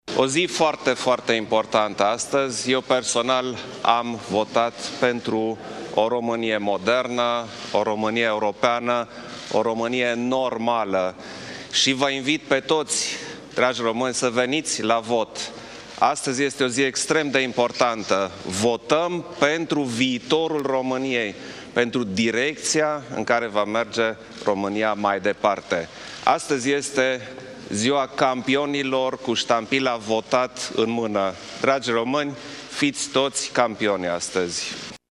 Actualul șef al statului, Klaus Iohannis candidat din partea PNL a votat în București, la ora 9,00, la Liceul ‘Jean Monnet’. Klaus Iohannis a declarat că a votat pentru o țară modernă, europeană și normală: